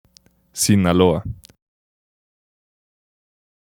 Sinaloa (Spanish pronunciation: [sinaˈloa]